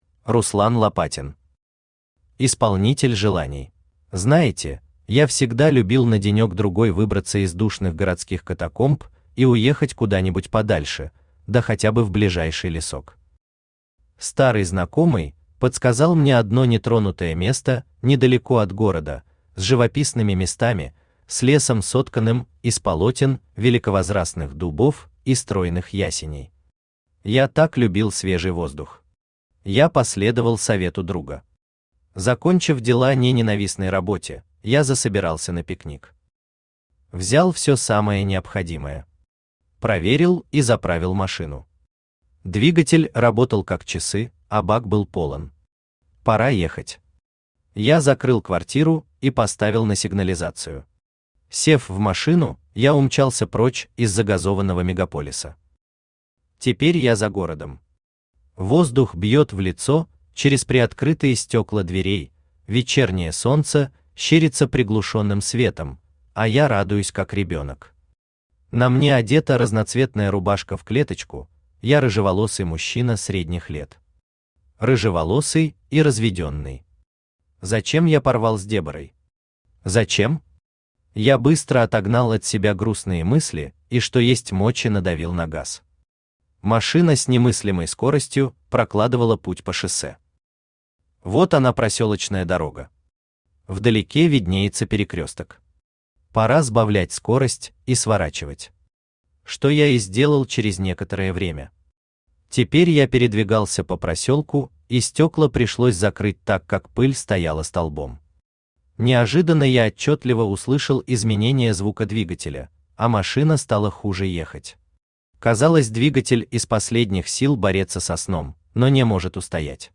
Аудиокнига Исполнитель желаний | Библиотека аудиокниг
Aудиокнига Исполнитель желаний Автор Руслан Лопатин Читает аудиокнигу Авточтец ЛитРес.